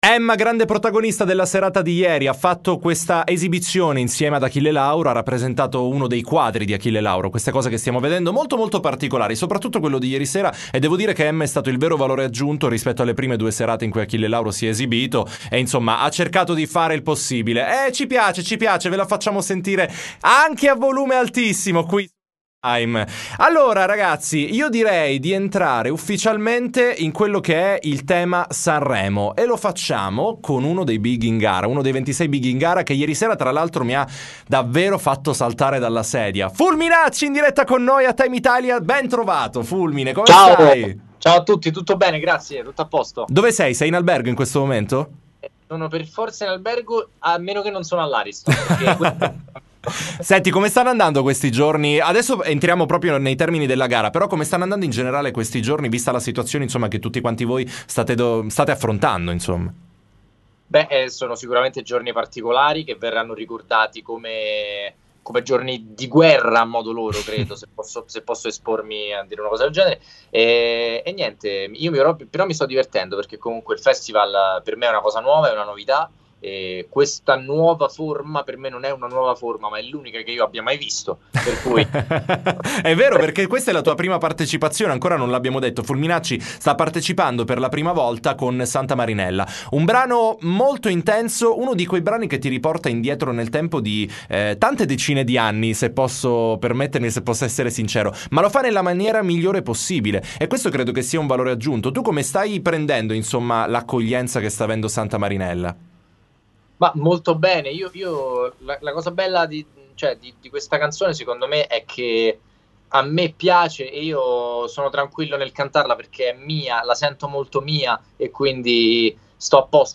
T.I. Intervista Fulminacci